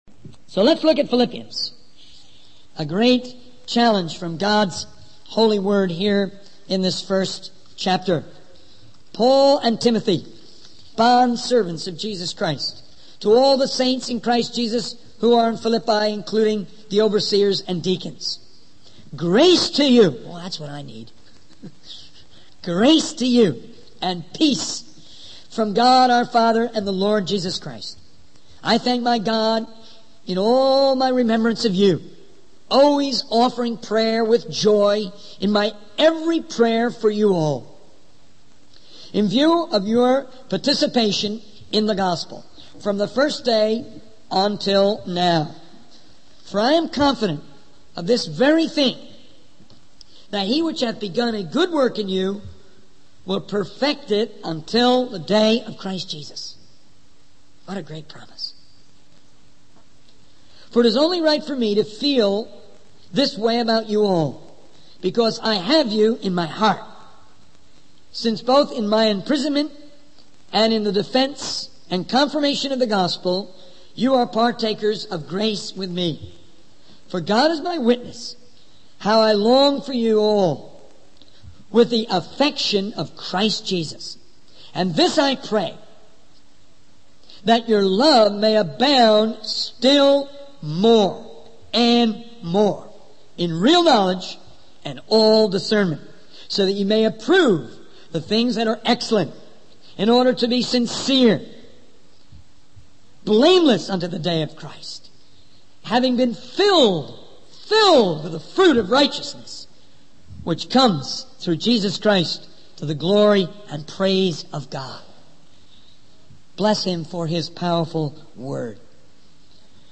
In this sermon, the speaker emphasizes the importance of discipline in the lives of believers. He encourages the audience to have more discipline in their lives, along with more life and more vision.